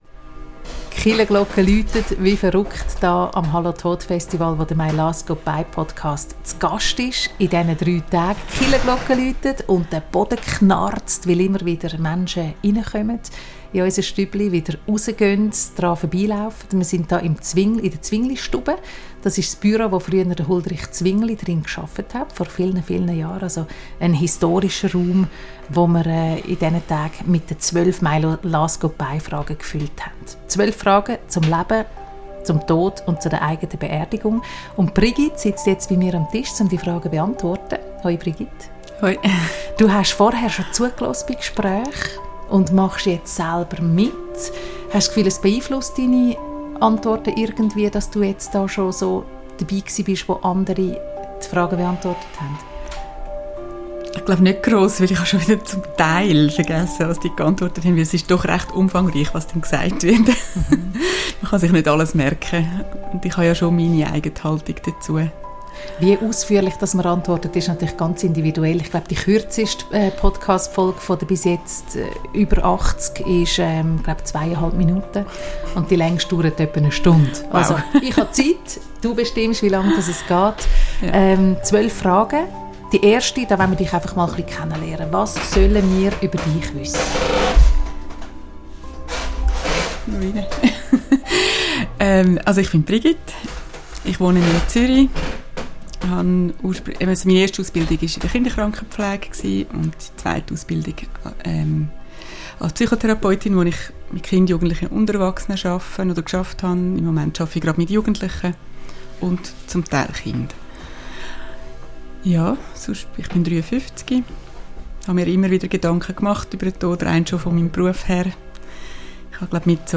aufgenommen am «Hallo Tod!»-Festival in Zürich.